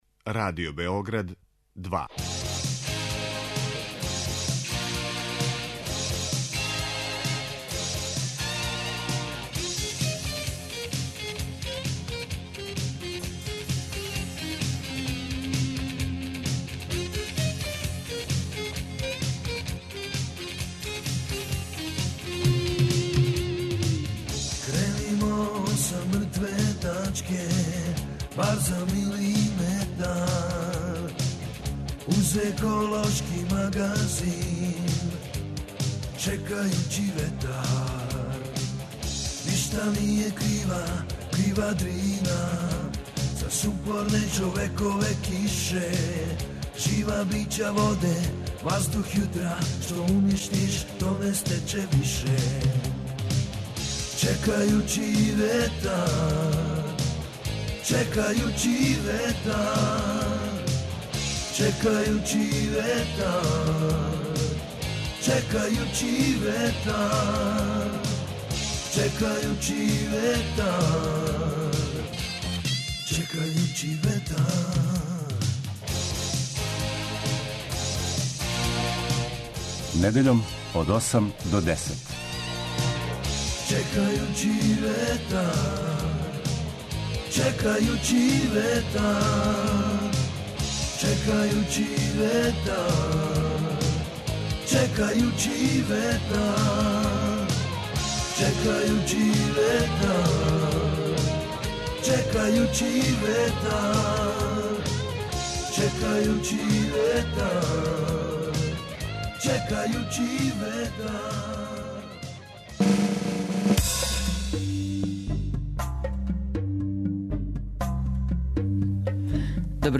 О овим и другим сликама Пирота, говориће председник ове општине - магистар Владан Васић.